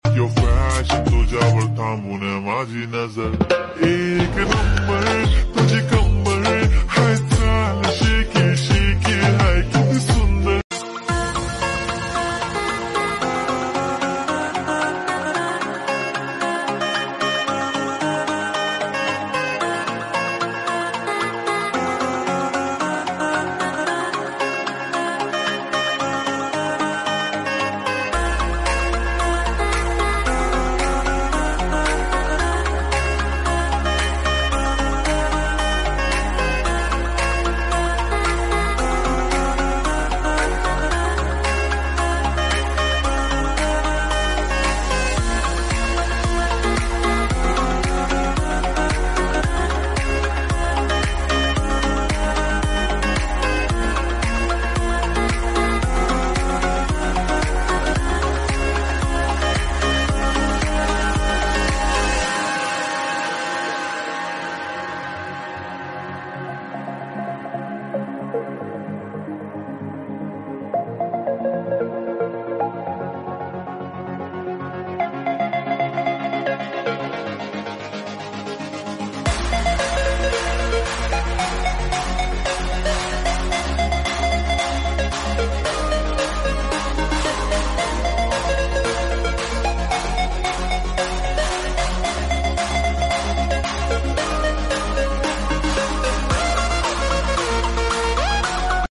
new tapeball stadium cricket editing sound effects free download